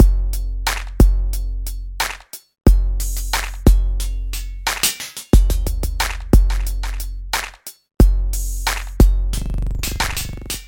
旧学校 鼓循环3
Tag: 90 bpm Hip Hop Loops Drum Loops 1.80 MB wav Key : Unknown